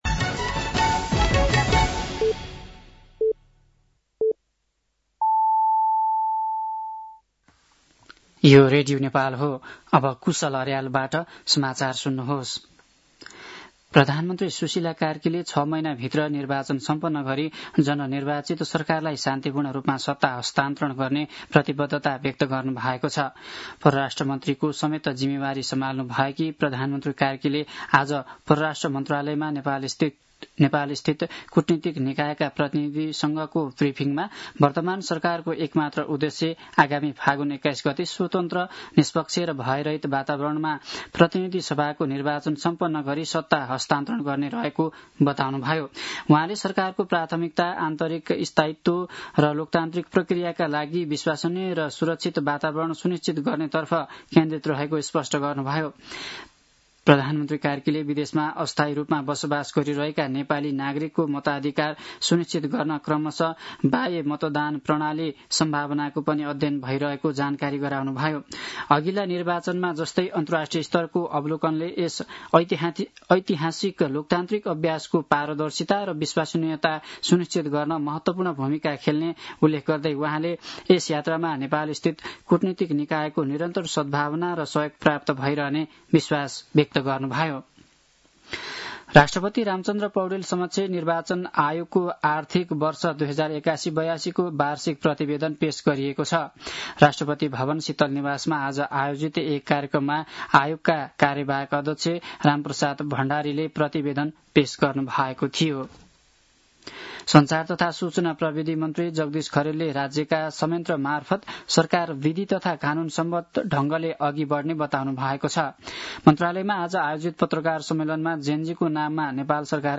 साँझ ५ बजेको नेपाली समाचार : ३१ असोज , २०८२
5-pm-nepali-news-6-31.mp3